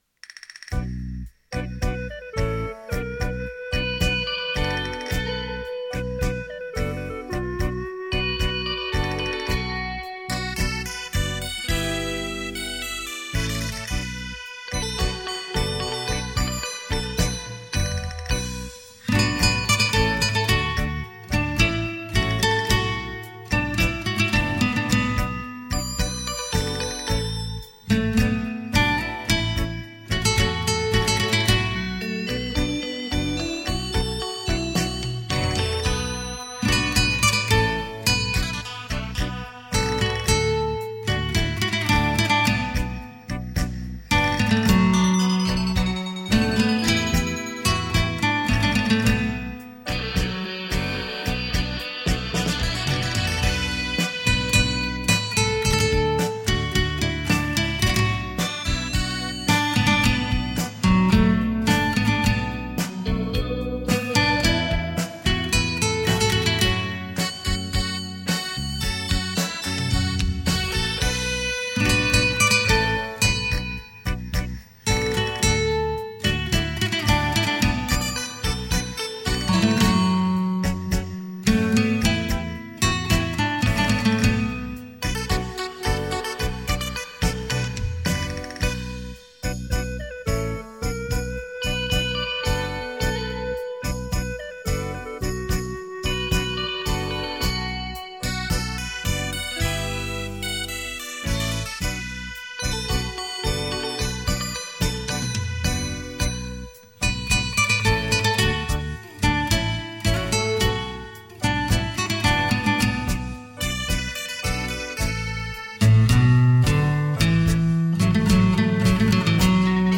久盛不衰的台语金曲，超立体电子琴完美演绎；